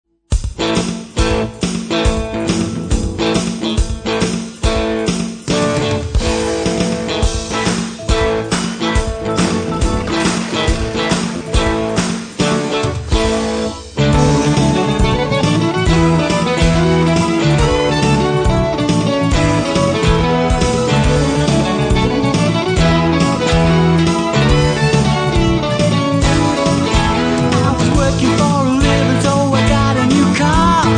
humorous rock